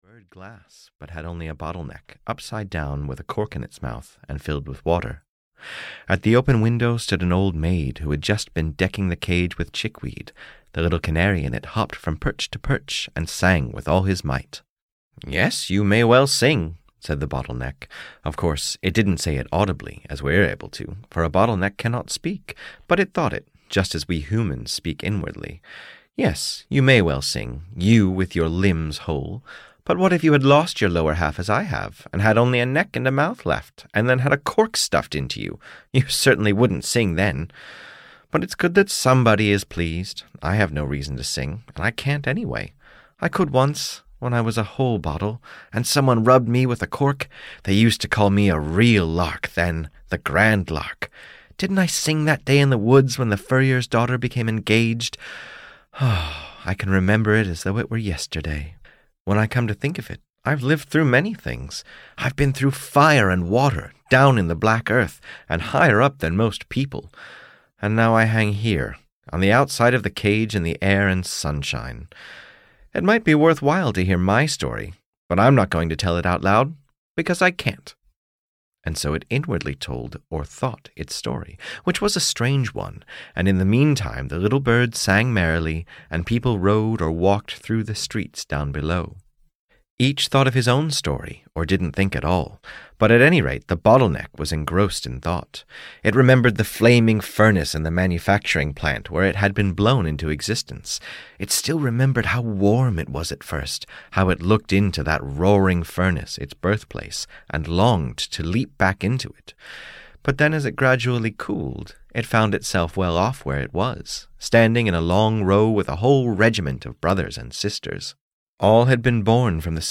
The Bottle Neck (EN) audiokniha
Ukázka z knihy